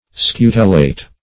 Scutellate \Scu"tel*late\, Scutellated \Scu"tel*la`ted\, a. [L.